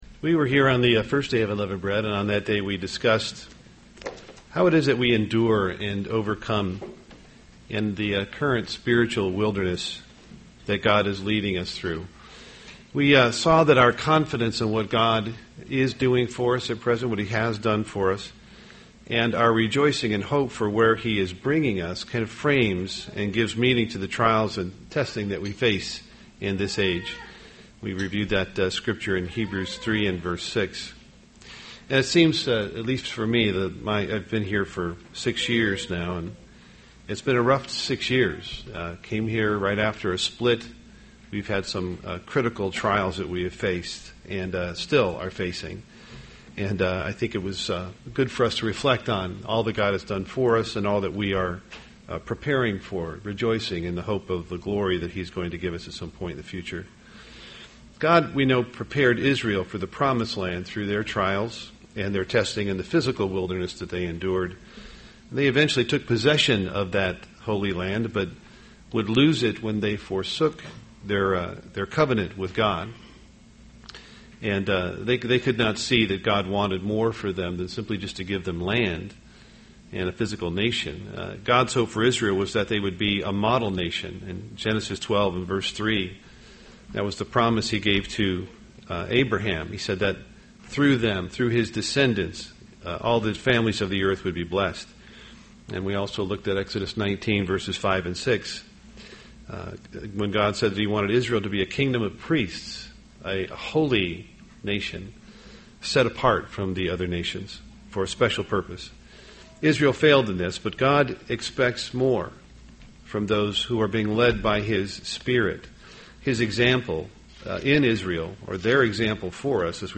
UCG Sermon Unleavened Bread righteousness spirit of God Studying the bible?